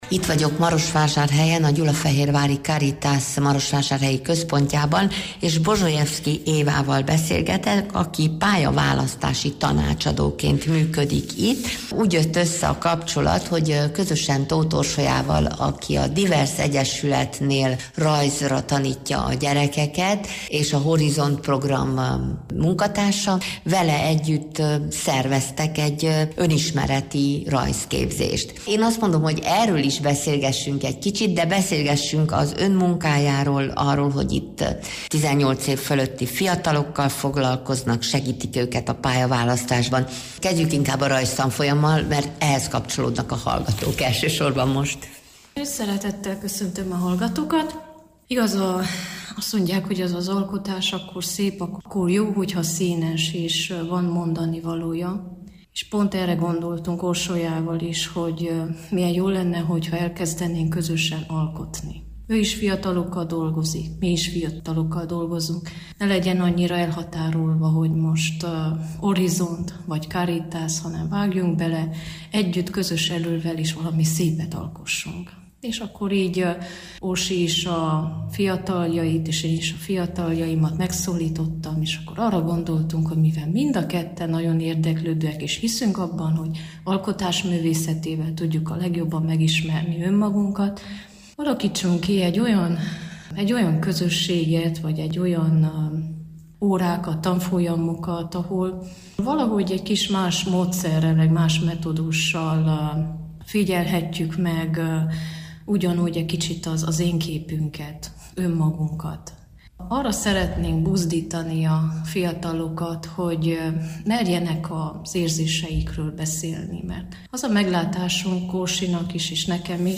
A műsor 2026. január 17-én hangzott el a rádióban.